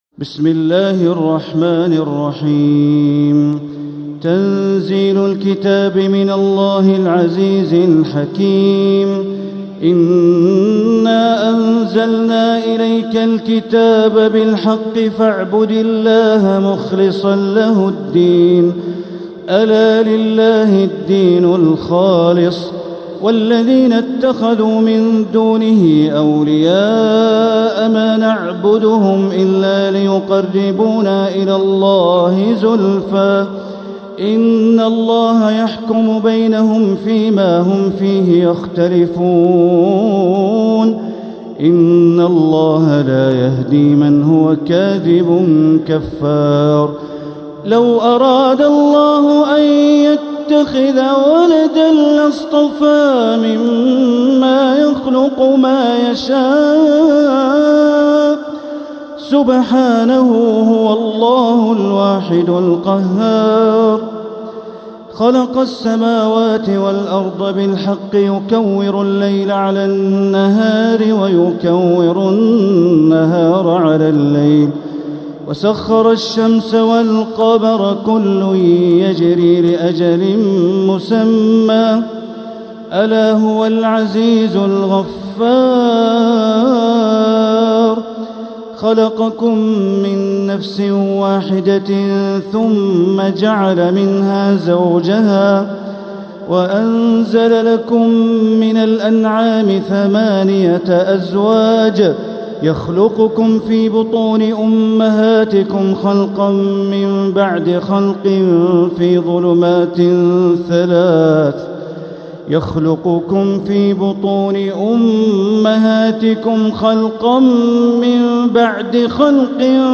سورة الزمر | مصحف تراويح الحرم المكي عام 1446هـ > مصحف تراويح الحرم المكي عام 1446هـ > المصحف - تلاوات الحرمين